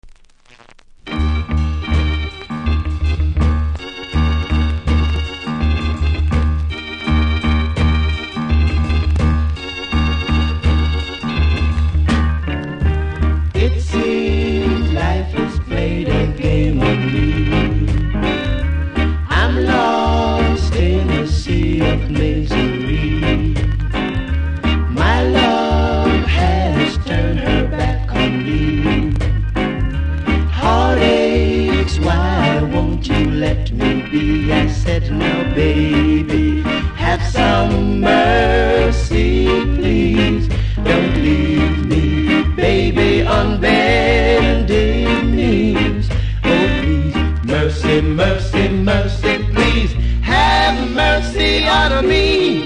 両面全体的にキズあり盤の見た目悪いですが、多少のノイズは気にならない方ならプレイ可レベル。